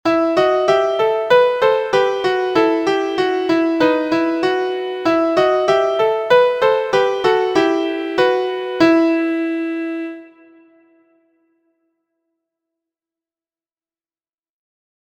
Singing and playing in parts, two part harmony, raised/sharped
• Key: E minor – pitched in G Major
• Time: 2/4
• Form: AB
• Recorder: advanced: introducing D# and F# while playing a duet